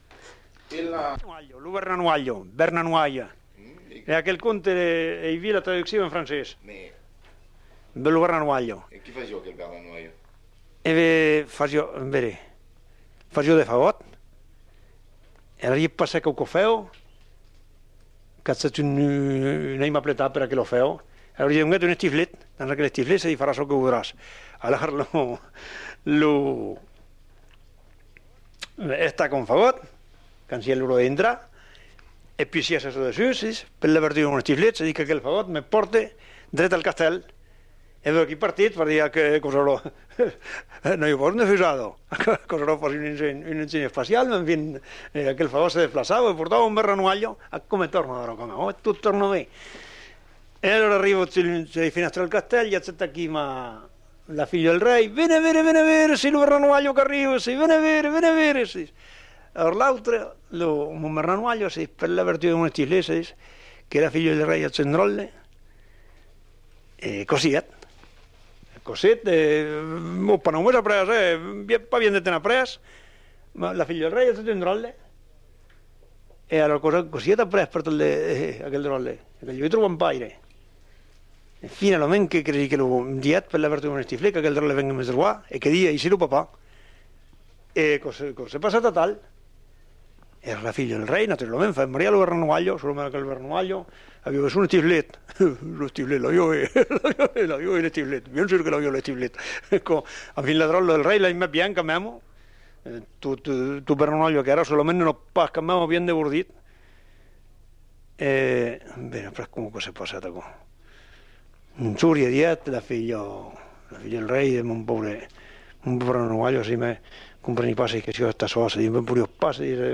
Genre : conte-légende-récit
Effectif : 1
Type de voix : voix d'homme
Production du son : parlé